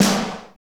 Index of /90_sSampleCDs/Northstar - Drumscapes Roland/SNR_Snares 1/SNR_F_T Snrs x